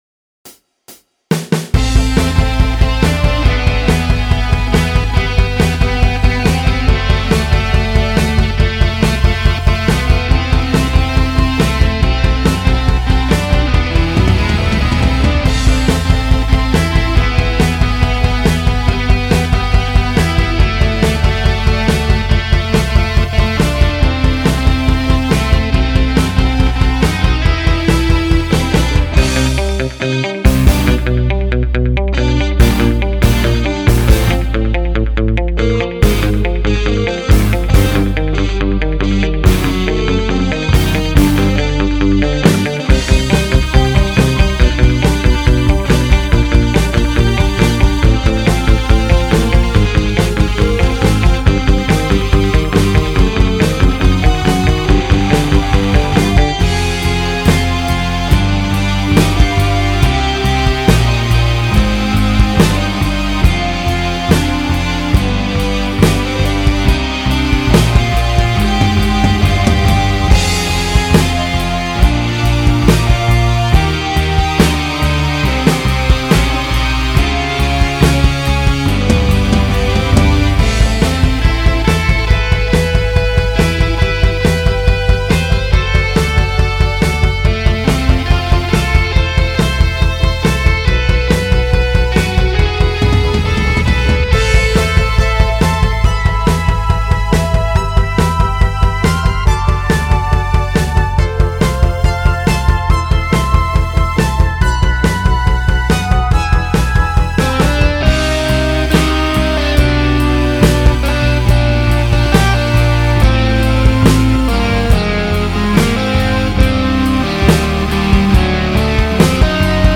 Pour être franc, je trouve ce style un peu trop prévisible / commercial à mon goût, mais je reconnais que tu as de la technique et que tu sais équilibrer le son.
Le coté commercial "old school", répétitif avec des enchaînements simples (mais efficaces, je l'espère) est en effet ce que je recherche.